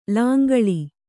♪ lāngaḷi